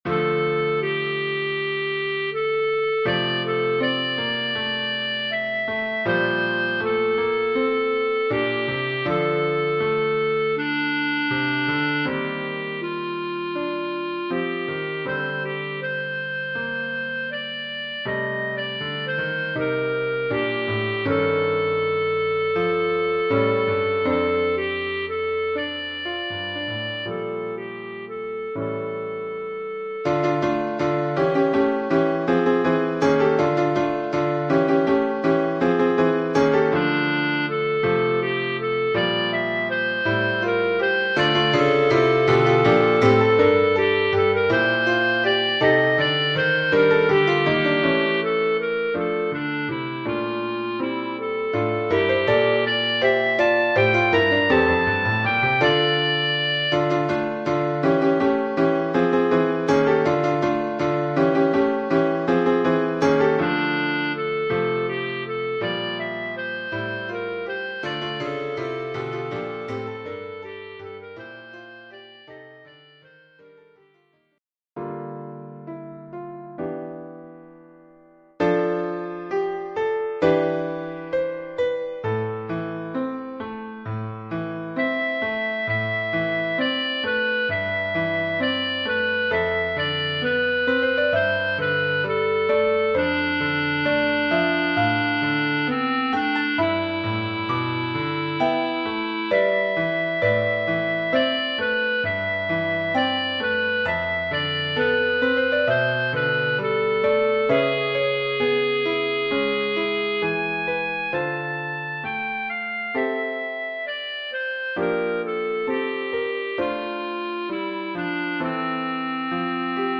Oeuvre en trois mouvements,